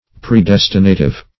Search Result for " predestinative" : The Collaborative International Dictionary of English v.0.48: Predestinative \Pre*des"ti*na*tive\, a. Determining beforehand; predestinating.